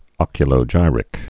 (ŏkyə-lō-jīrĭk)